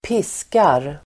Uttal: [²p'is:kar]